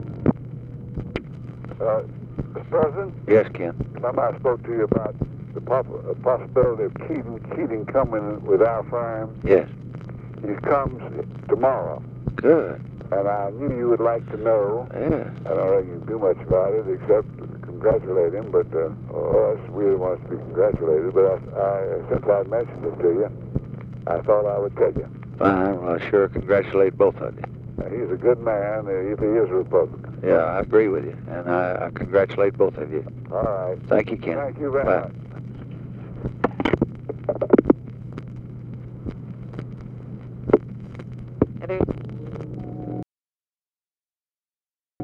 Conversation with KENNETH ROYALL, March 31, 1965
Secret White House Tapes